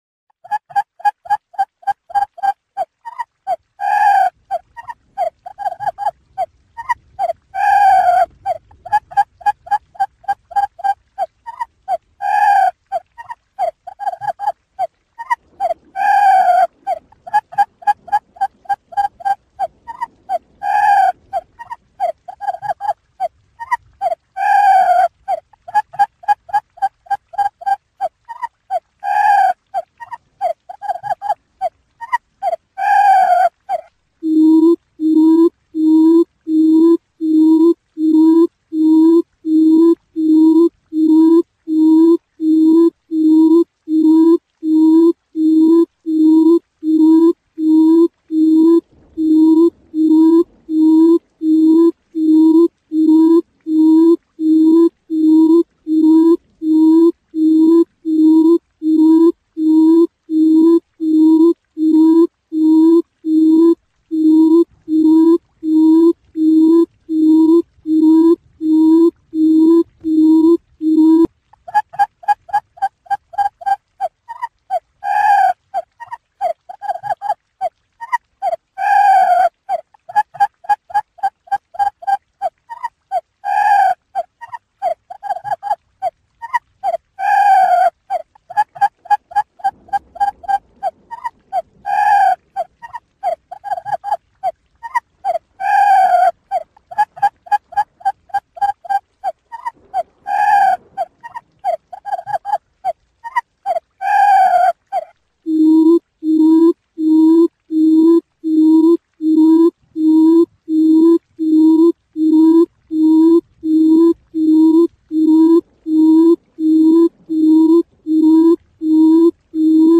เสียงนกคุ้มอืดตัวผู้
หมวดหมู่: เสียงนก
คำอธิบาย: นี่คือไฟล์ mp3 quail bait มาตรฐานที่ไม่มีเสียงรบกวนซึ่งเป็นไฟล์ Zing mp3 quail ที่ดีที่สุด